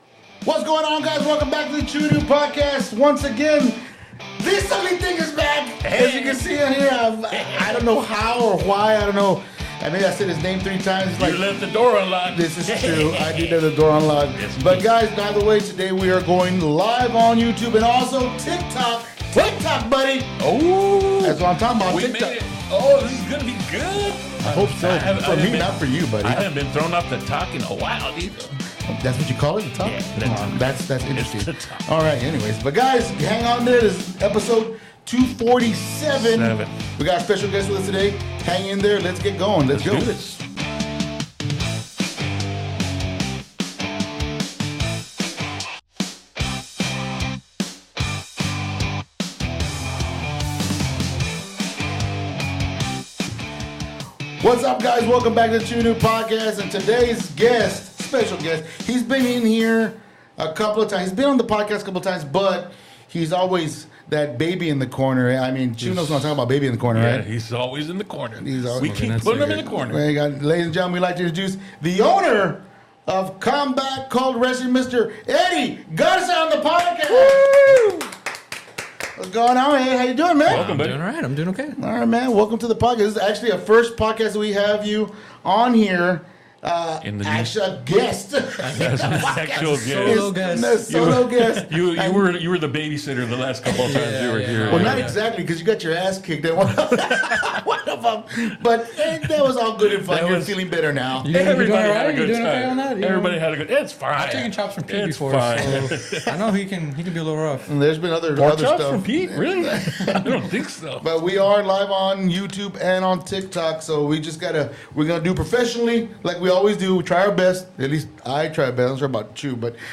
Join us for a thrilling and entertaining ride as we bring you exclusive interviews with local talent, businesses, artists, actors, and directors from the RGV 956.